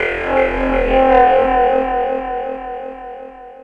02-Vocodomod.wav